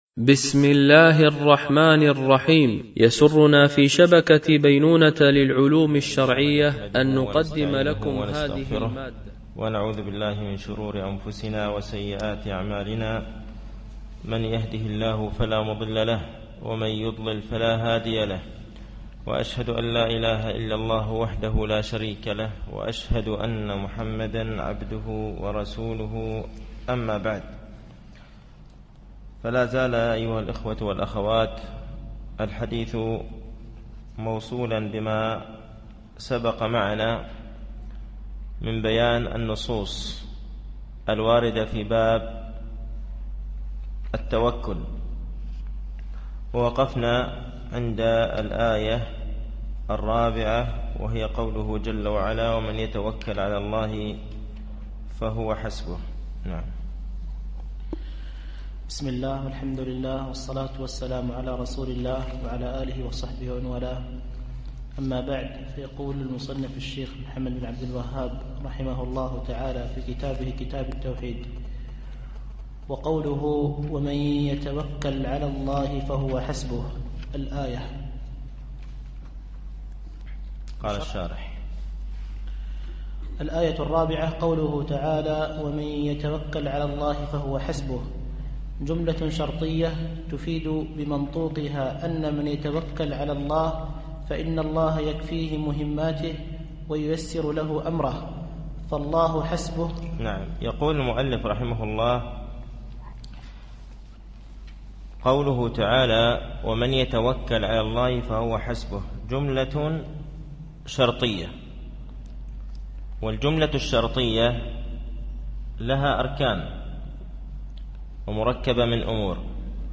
التعليق على القول المفيد على كتاب التوحيد ـ الدرس الثامن بعد المئة